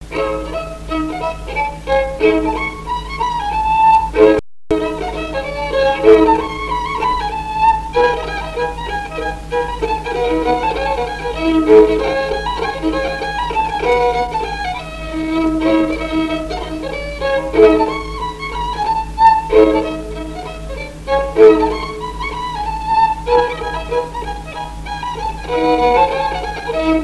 Chants brefs - A danser
danse : mazurka
Pièce musicale inédite